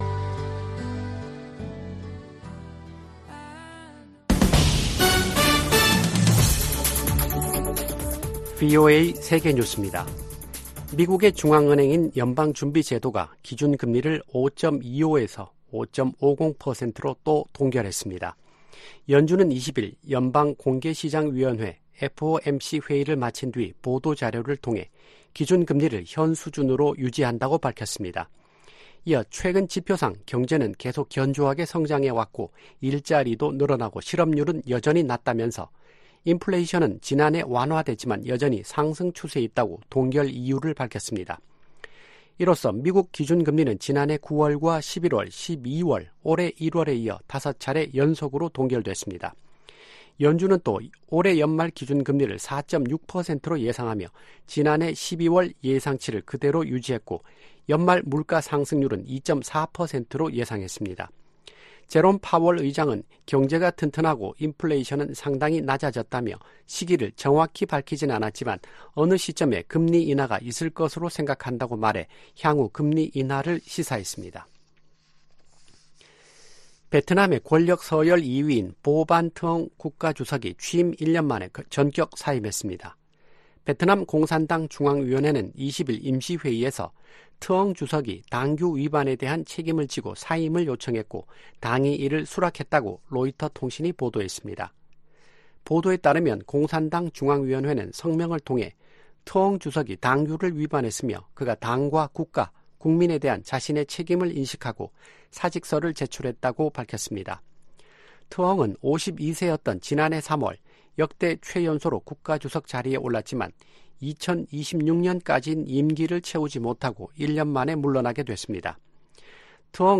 VOA 한국어 아침 뉴스 프로그램 '워싱턴 뉴스 광장' 2024년 3월 21일 방송입니다. 북한이 신형 중장거리 극초음속 미사일에 사용할 다단계 고체연료 엔진 시험에 성공했다고 관영 매체들이 보도했습니다.